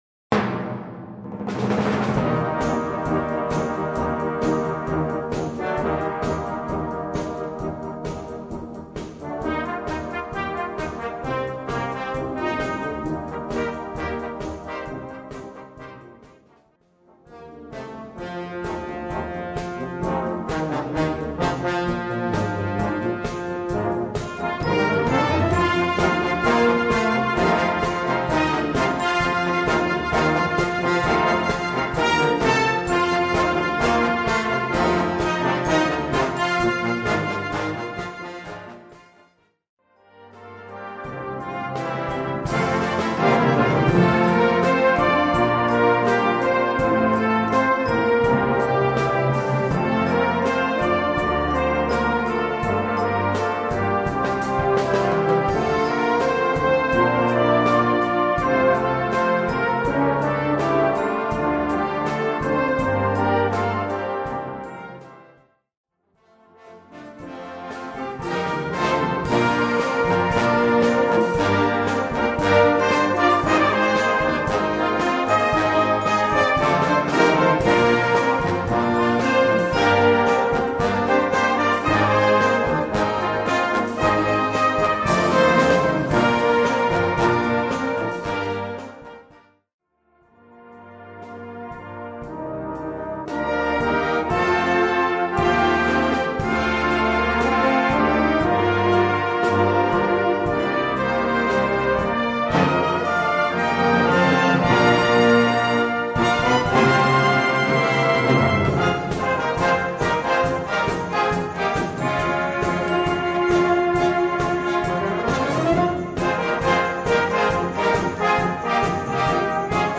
Gattung: Potpourri
A4 Besetzung: Blasorchester Zu hören auf
PDF: Tonprobe: Ein Medley mit Superhits aus den 80er-Jahren.